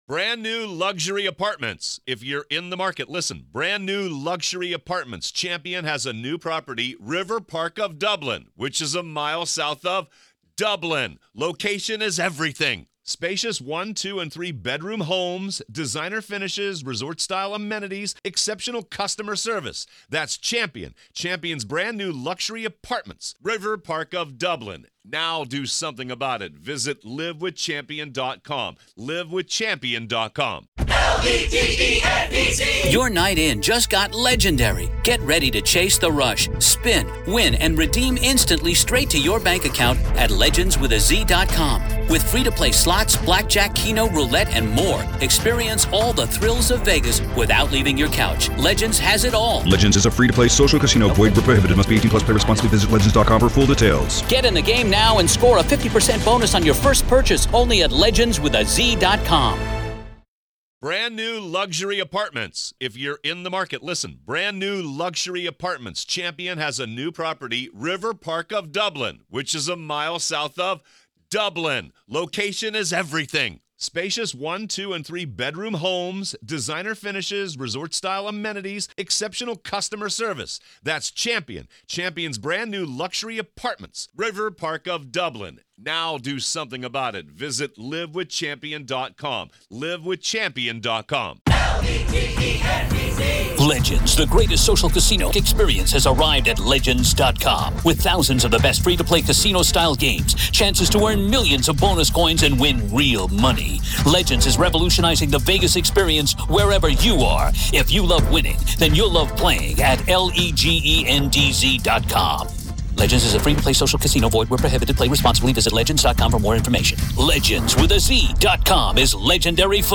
In this unflinching conversation